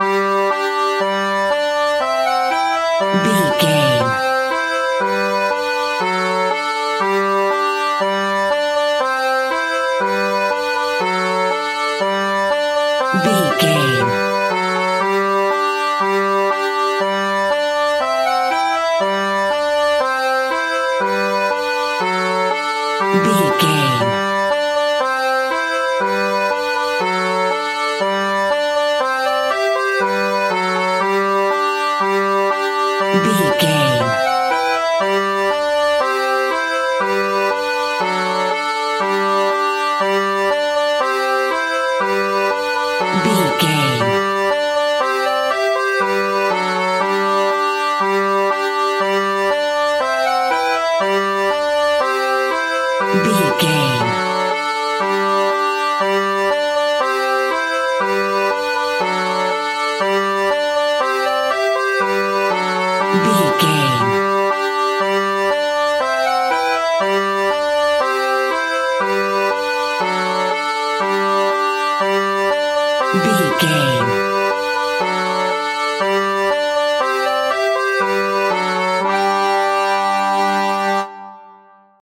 Ionian/Major
nursery rhymes
kids music